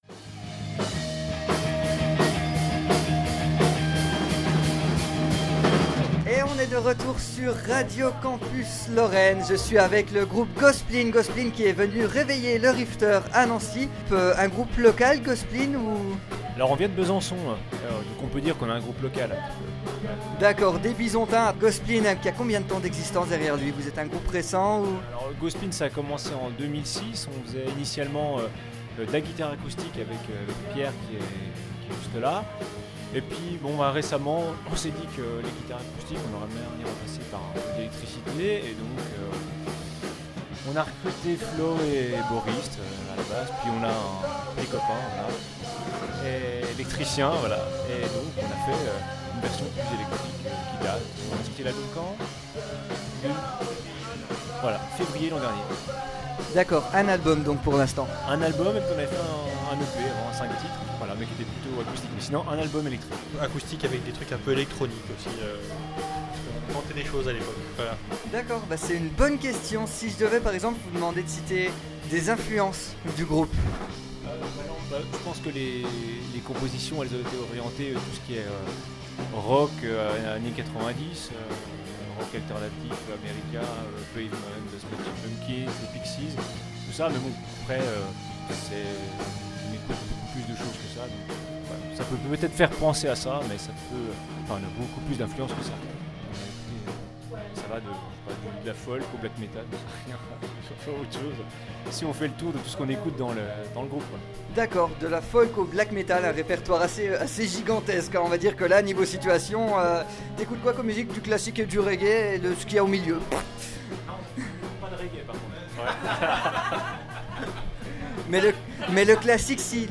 Un groupe que Radio Campus Lorraine vous invite à découvrir dans cette interview, ou même sur scène ce 24 février au Passagers du Zinc, à Besançon.
itw-go-spleen.mp3